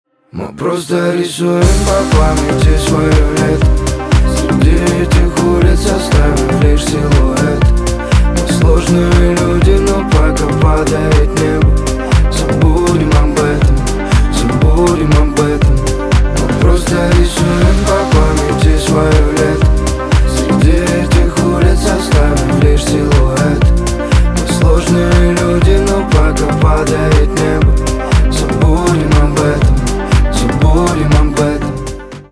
поп
чувственные
грустные
битовые , басы